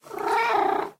Sound / Minecraft / mob / cat
purreow2.ogg